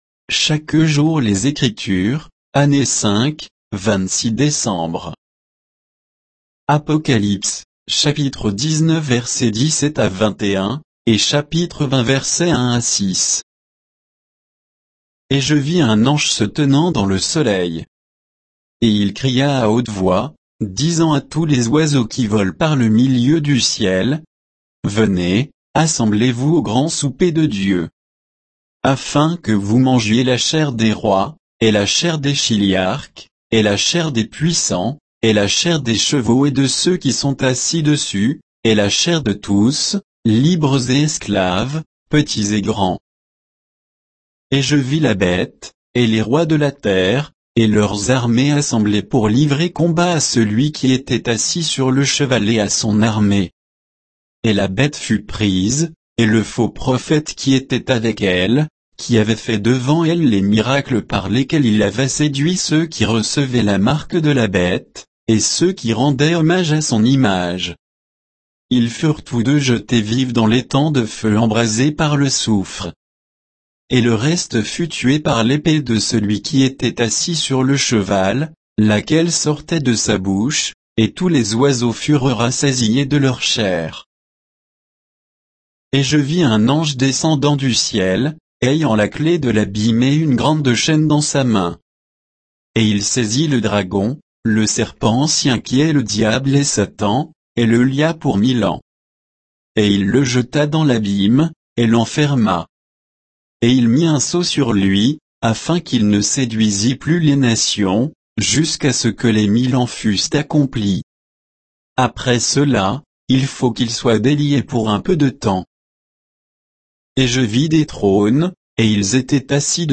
Méditation quoditienne de Chaque jour les Écritures sur Apocalypse 19